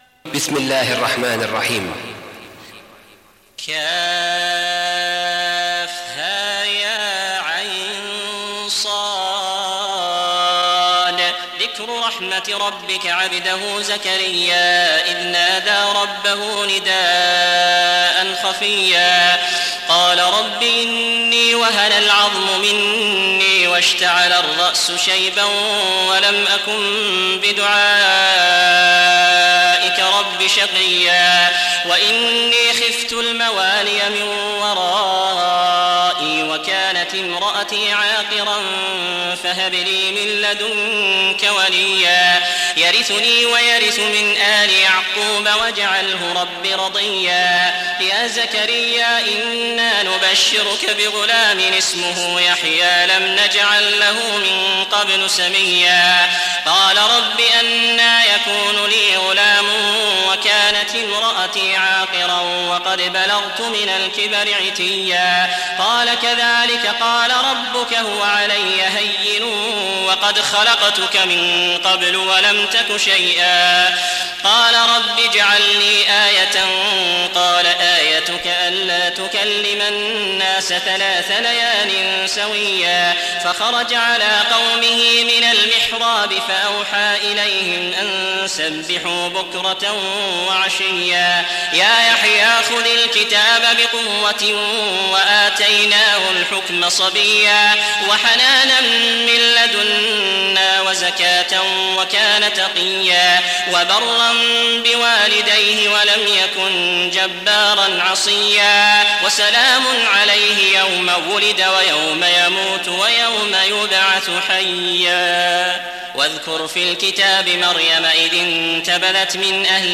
برواية حفص عن عاصم